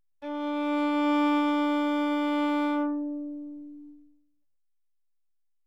violin_openD.wav